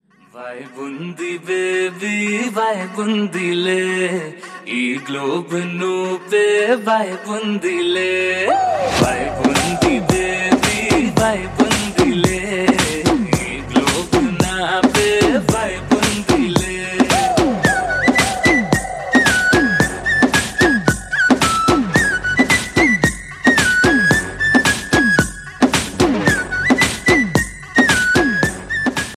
flute
calm and melodic vibe